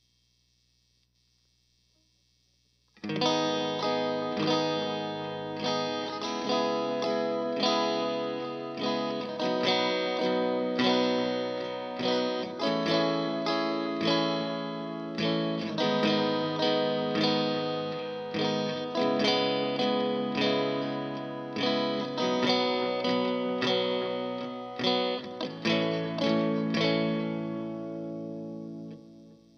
Guitar_PalmTrees_75bpm_Abm
guitar_palmtrees_75bpm_abm